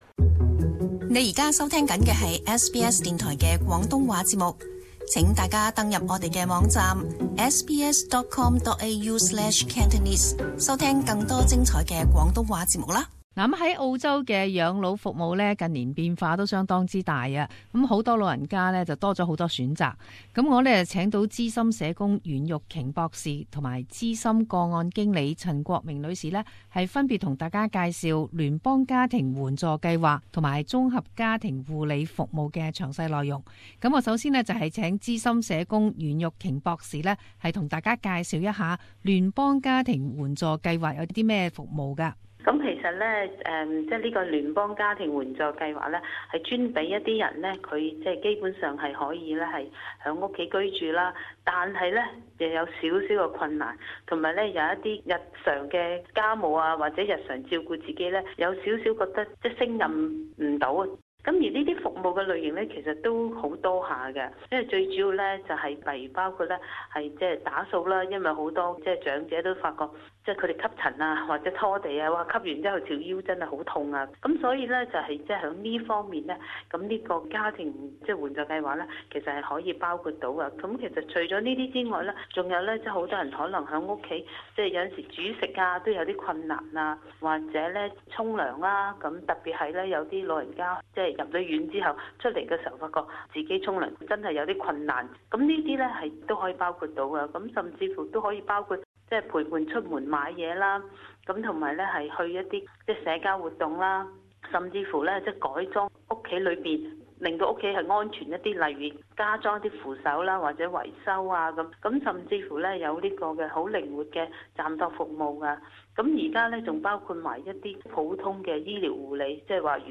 【社團專訪】不同階段的養老服務